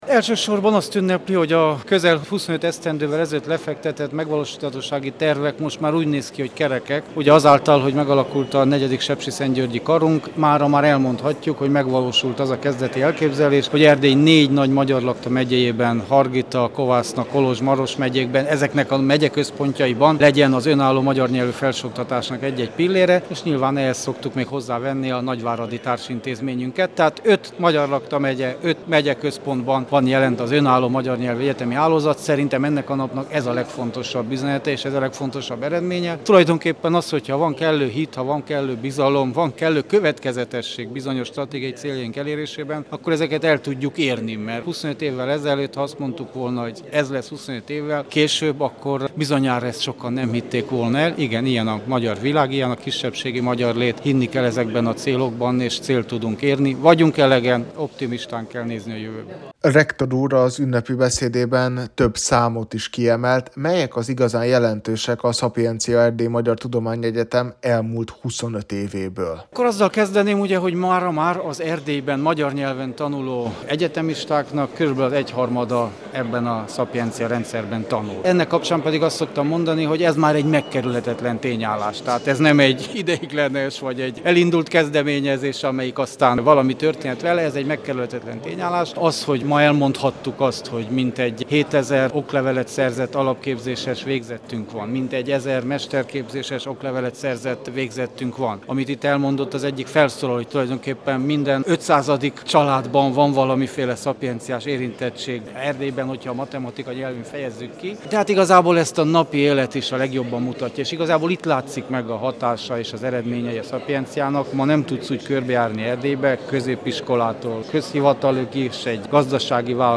Az immáron hagyományosnak számító ünnepséget idén október 7-én, szombaton tartották.